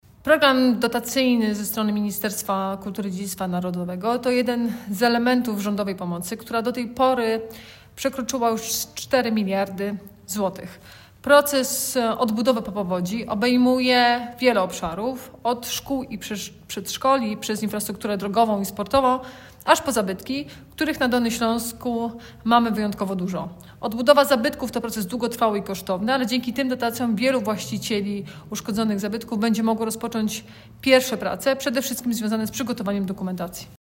– Ten program dotacyjny to jeden z elementów rządowej pomocy, która przekroczyła już 4 miliardy złotych – podkreśla Anna Żabska, wojewoda dolnośląska.